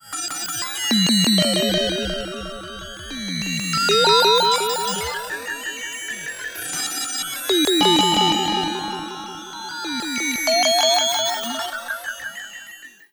Stereo Modulations.wav